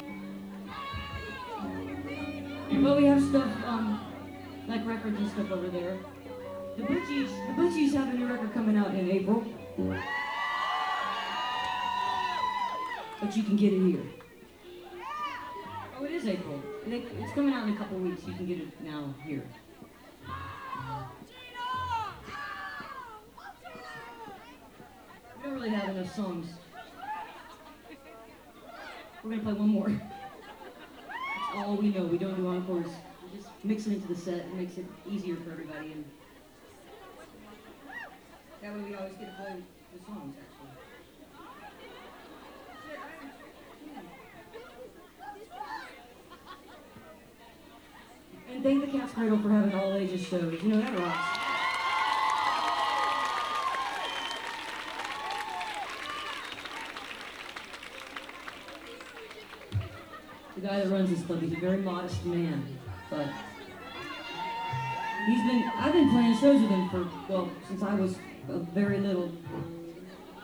lifeblood: bootlegs: 2001-04-03: cat's cradle - carrboro, north carolina (amy ray and the butchies)
18. talking with the crowd (1:10)